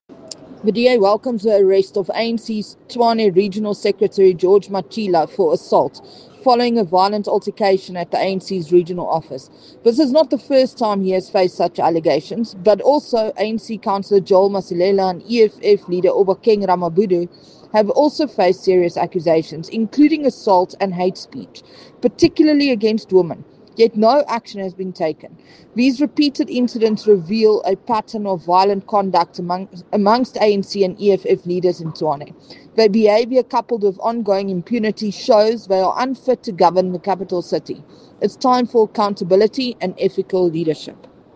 Note to Editors: Please find English and Afrikaans soundbites by Crezane Bosch MPL